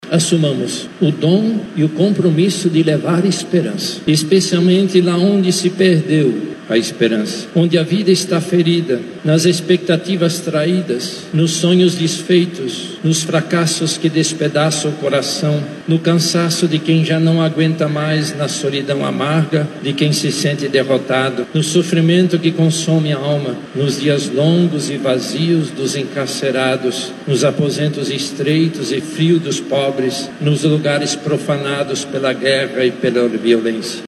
Durante a homilia, Dom Leonardo destacou o dom e o compromisso de levar esperança, especialmente onde se perdeu e para os que sofrem dias vazios.